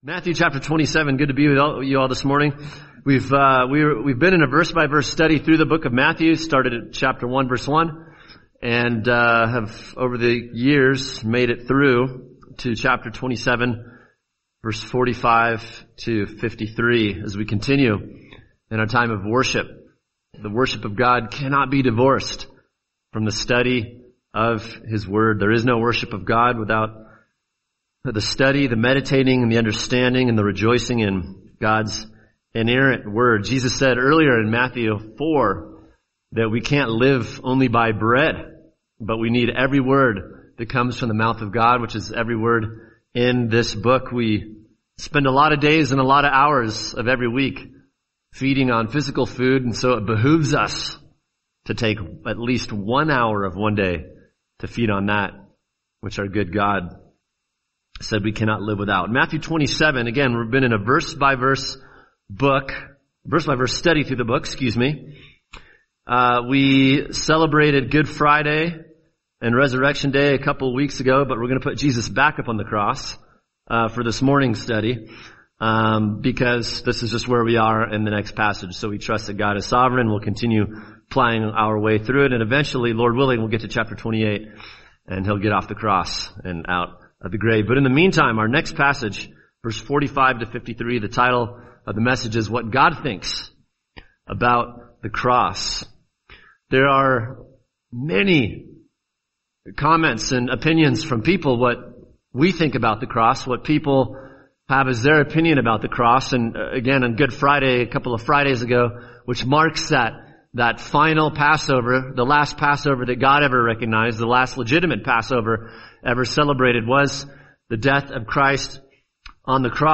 [sermon] Matthew 27:45-53 What God Thinks About The Cross – Part 1 | Cornerstone Church - Jackson Hole